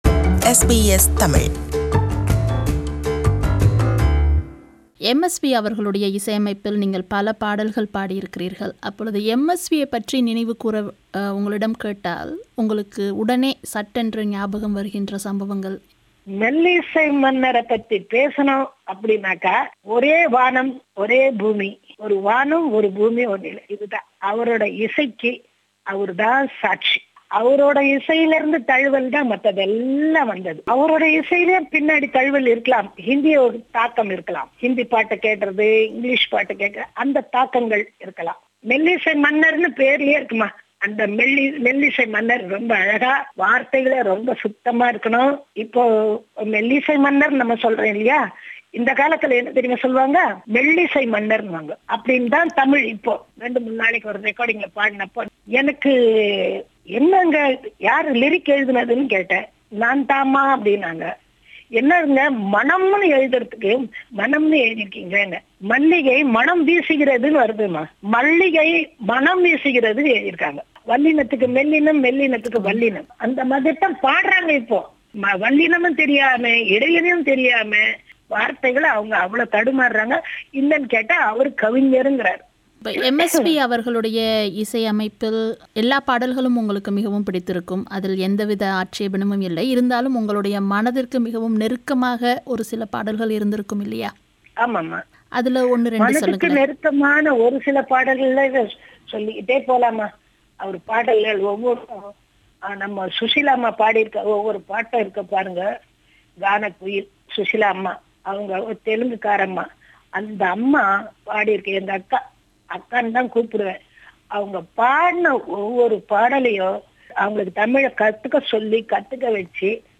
This is part II of her interview